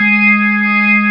55o-org12-G#3.aif